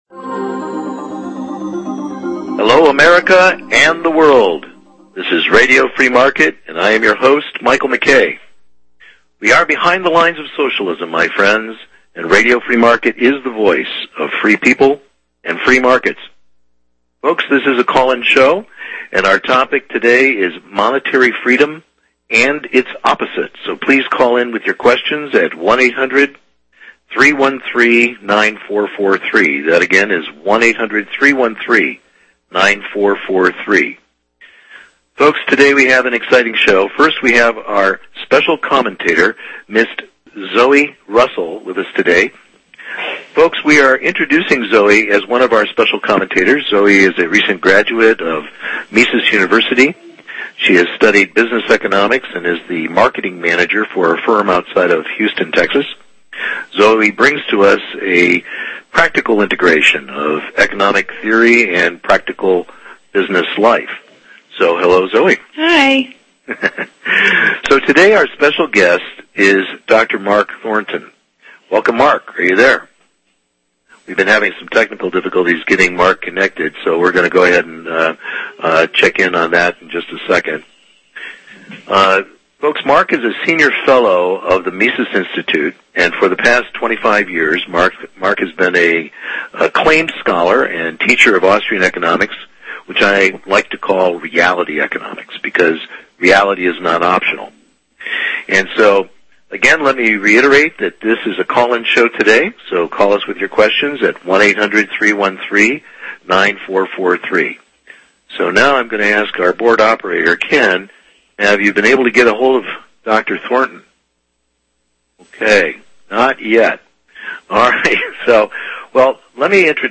This lively conversation will help all listeners learn and understand The Basics About Money That We Never Learned in School – and should have. This interview is the first of our Series The ABC’s of Money.